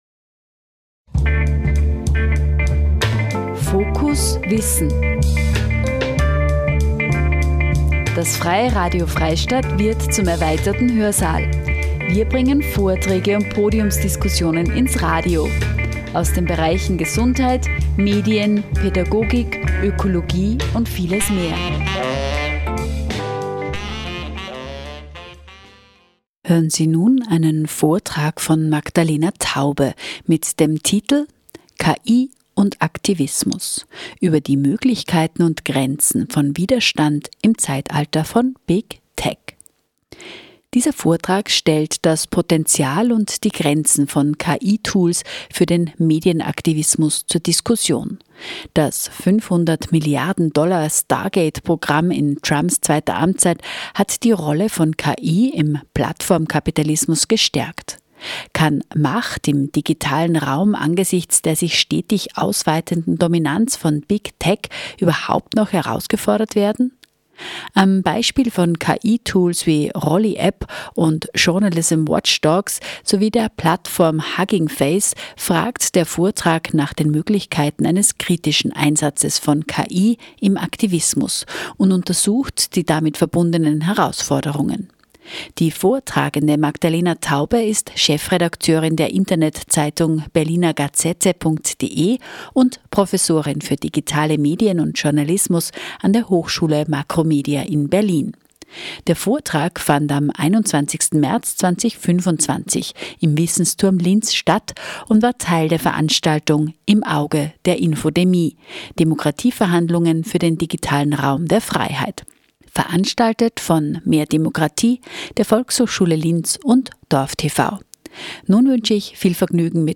Der Vortrag fand am 21.März 2025 im Wissensturm Linz statt und war Teil der Veranstaltung „Im Auge der Infodemie“ – Demokratieverhandlungen für den digitalen Raum der Freiheit, veranstaltet von mehr demokratie, der Volkshochschule Linz und dorfTV.